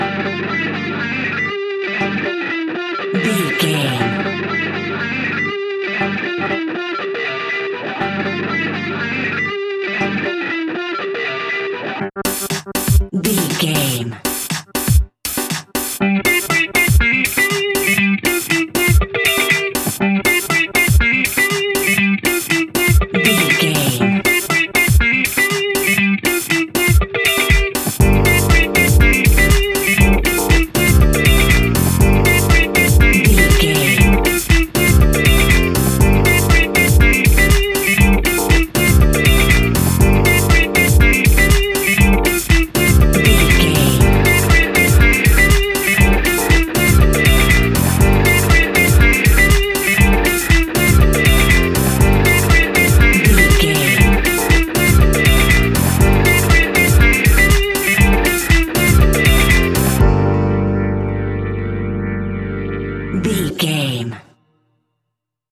Aeolian/Minor
energetic
hypnotic
groovy
drums
bass guitar
electric guitar
electric piano
disco house
electronic funk
synths
upbeat
synth bass
drum machines